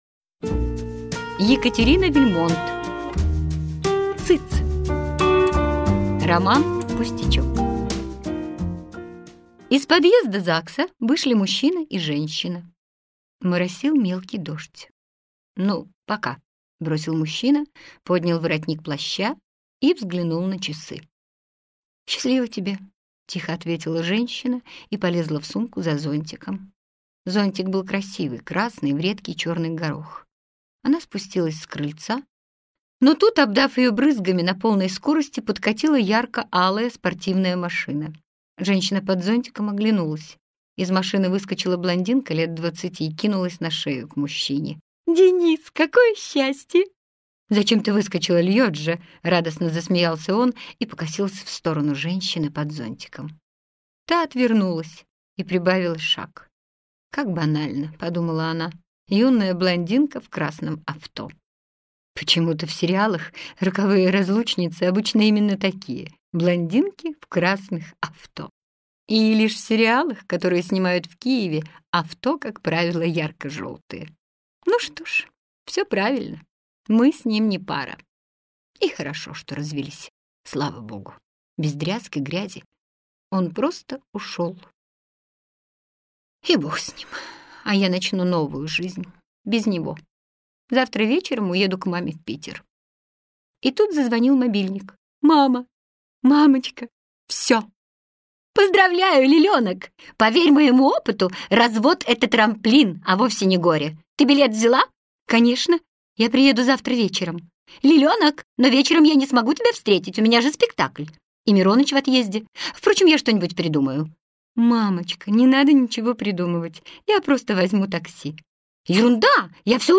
Аудиокнига Цыц!